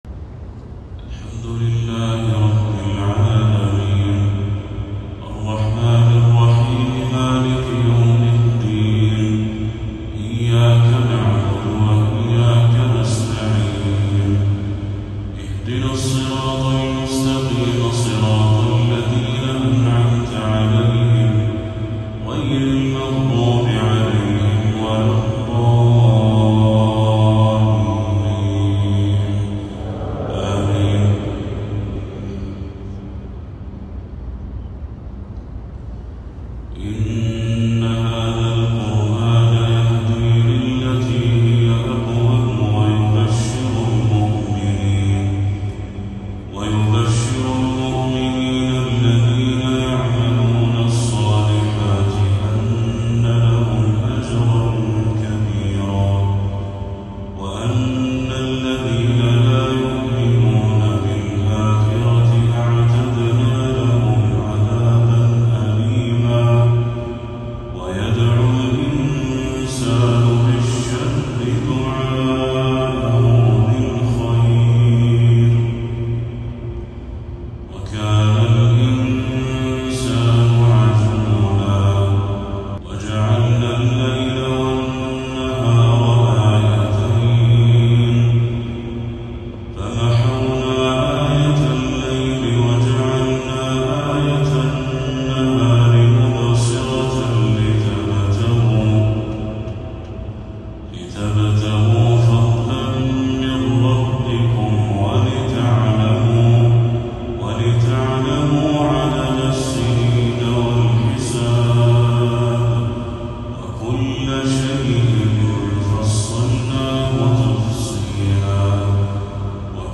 تلاوة رائعة من سورة الإسراء للشيخ بدر التركي | عشاء 29 صفر 1446هـ > 1446هـ > تلاوات الشيخ بدر التركي > المزيد - تلاوات الحرمين